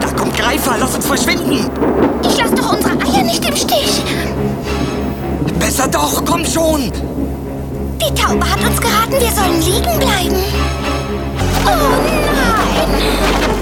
Ein paar Samples aus dem Trickfilm Oliver & Olivia, Anfang 1992 in Berlin synchronisiert.
Spatzeneltern
oli-2birds.mp3